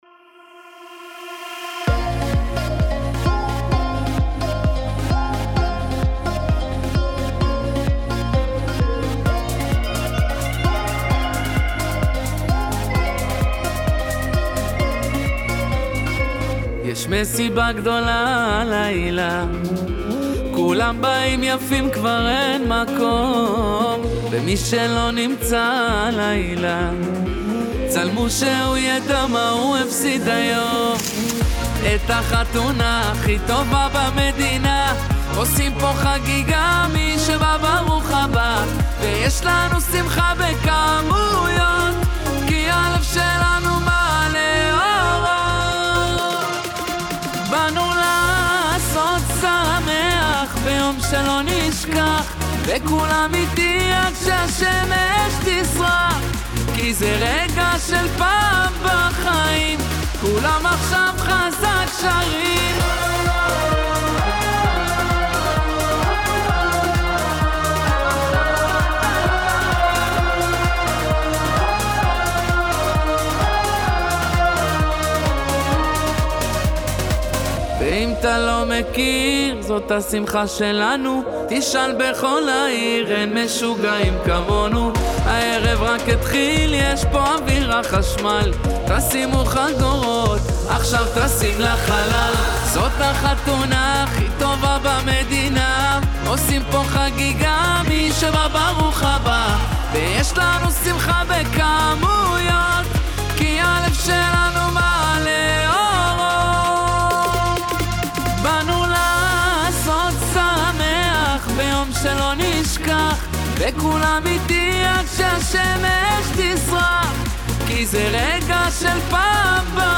קול מדהים בטירוף מילים עוצמתיות ממליץ לישמוע